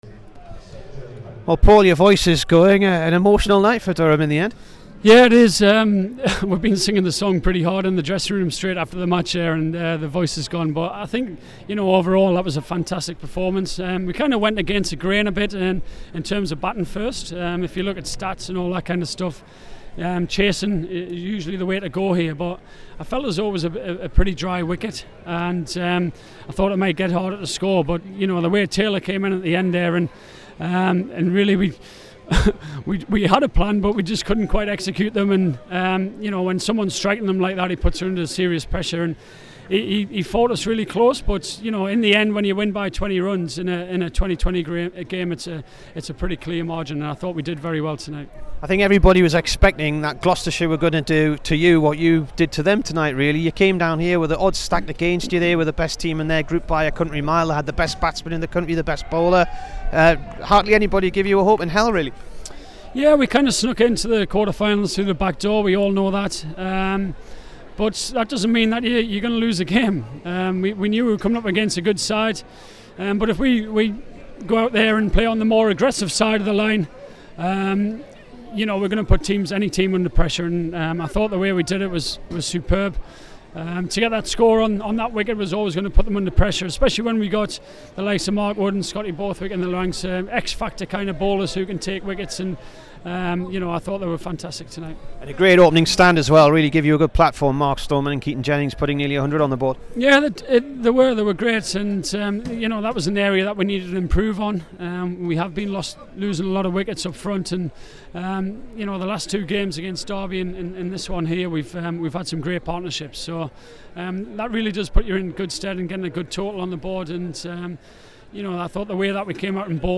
Here is the Durham skipper after the T20 win at Gloucestershire.